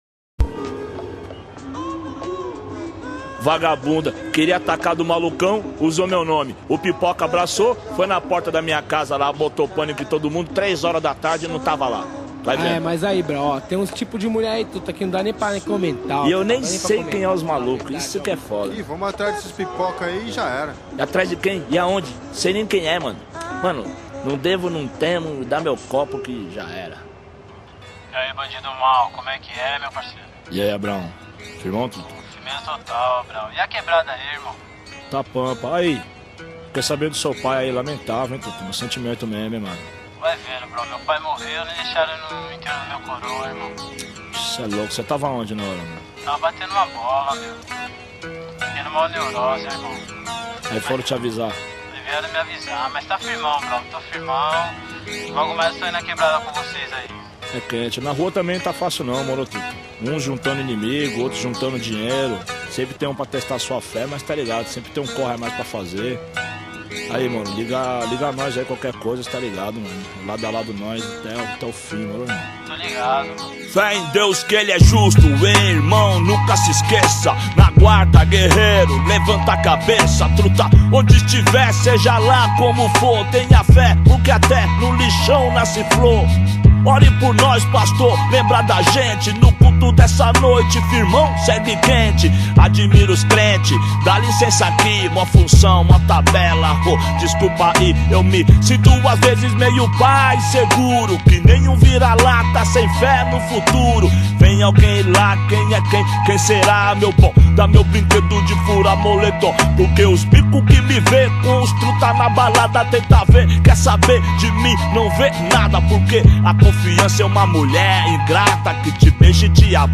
2024-11-25 16:57:46 Gênero: Rap Views